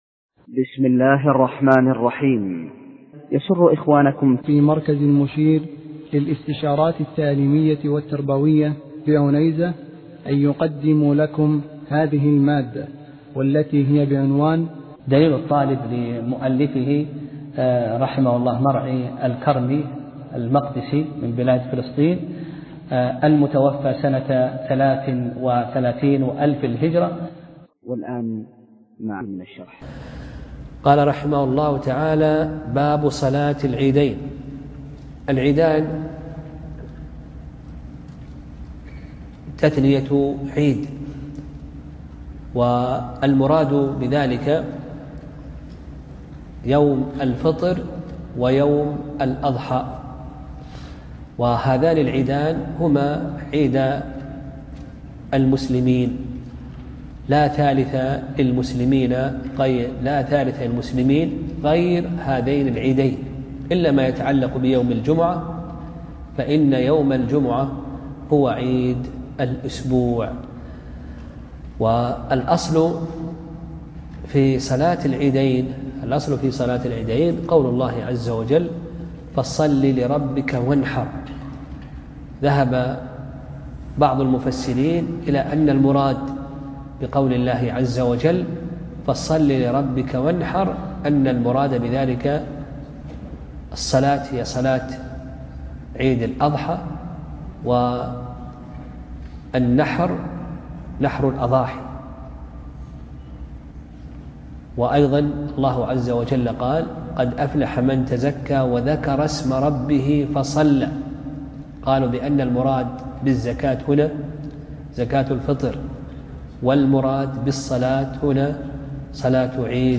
درس (12) : فصل في الإمامة (2)